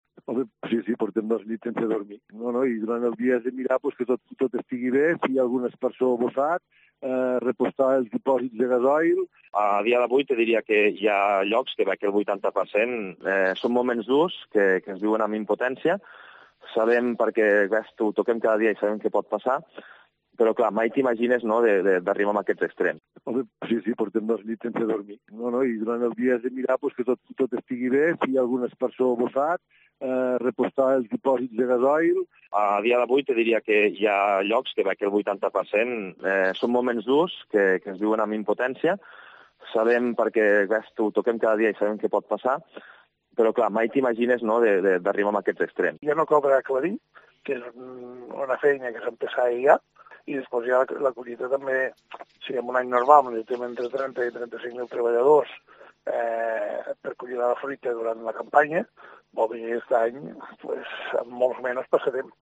Diversos agricultores de la zona de Lleida explican los efectos de la helada en las fincas